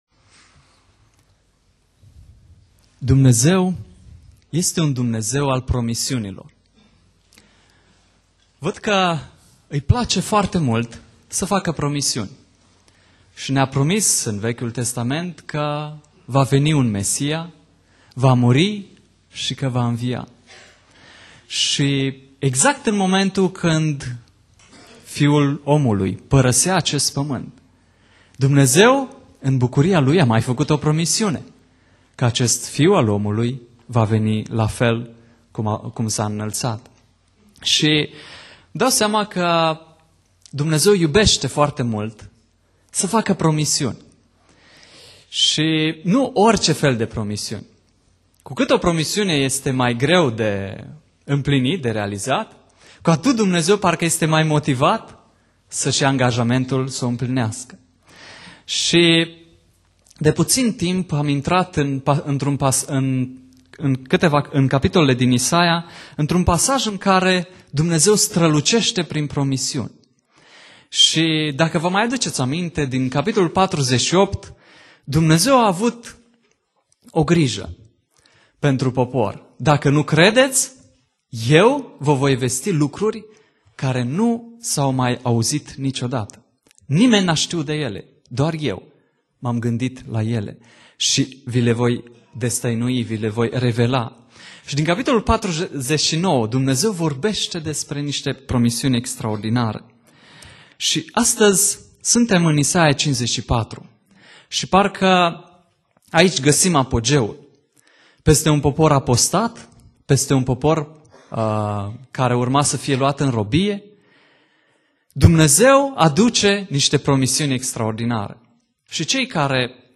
Predica Exegeza - Isaia 54